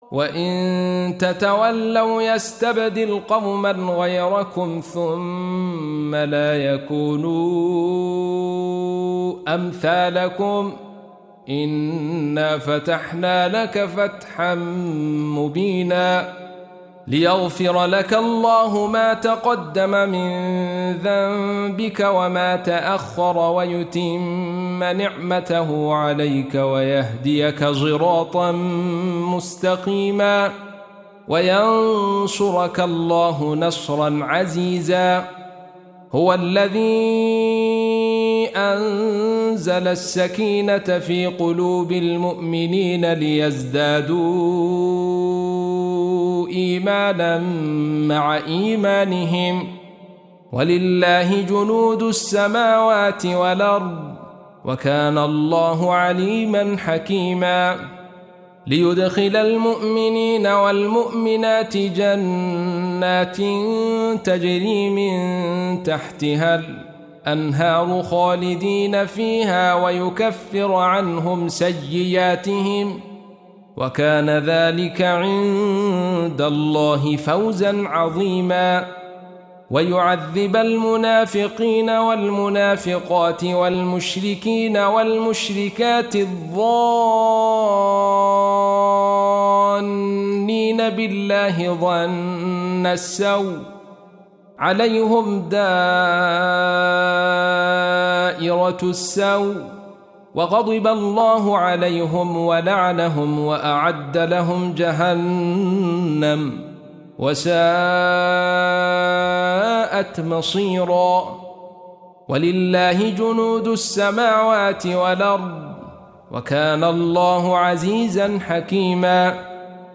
48. Surah Al-Fath سورة الفتح Audio Quran Tarteel Recitation
Surah Repeating تكرار السورة Download Surah حمّل السورة Reciting Murattalah Audio for 48. Surah Al-Fath سورة الفتح N.B *Surah Includes Al-Basmalah Reciters Sequents تتابع التلاوات Reciters Repeats تكرار التلاوات